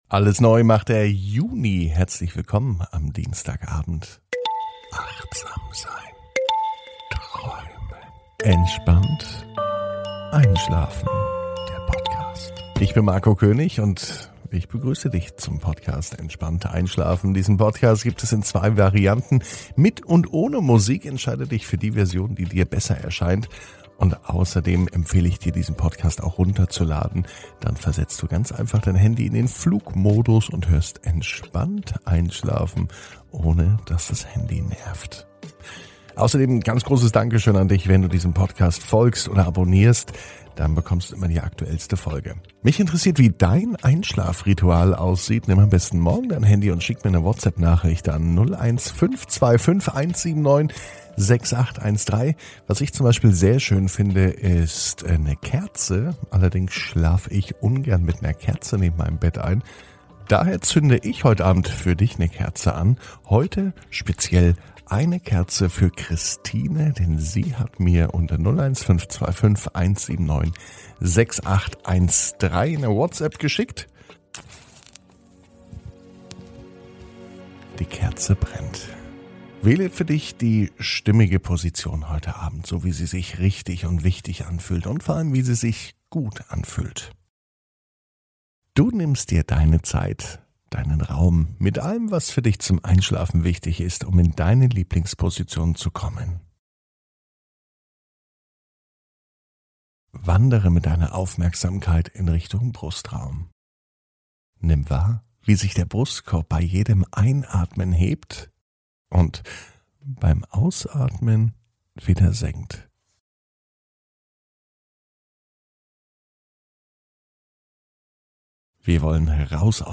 (ohne Musik) Entspannt einschlafen am Dienstag, 01.06.21 ~ Entspannt einschlafen - Meditation & Achtsamkeit für die Nacht Podcast